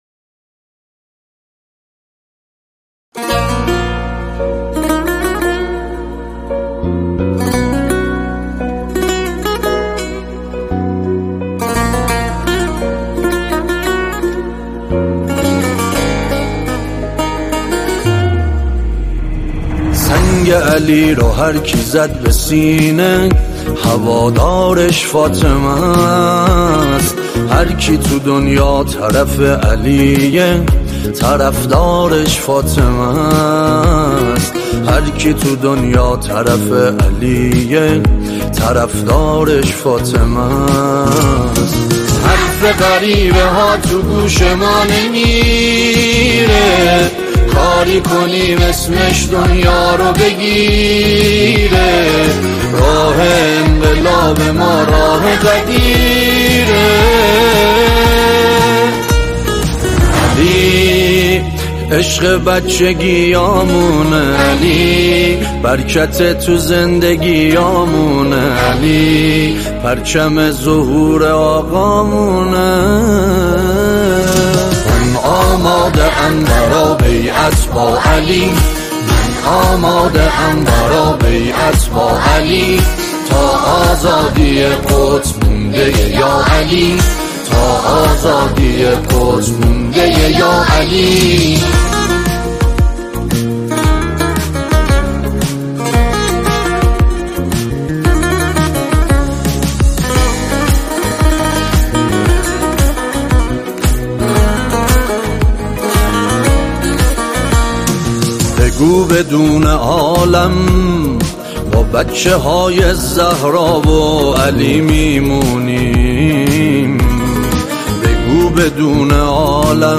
خواننده نواهای ملی و مذهبی
نماهنگ مذهبی